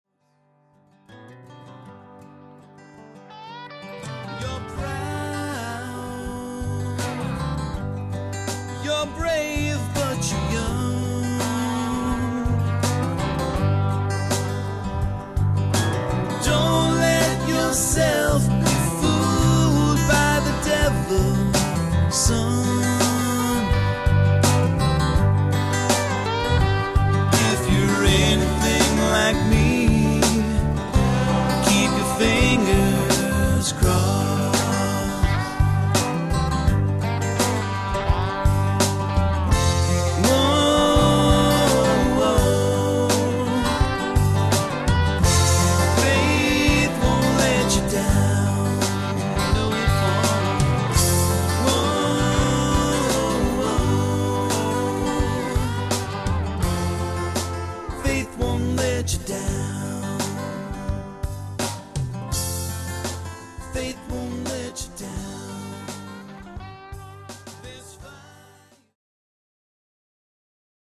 singer and songwriter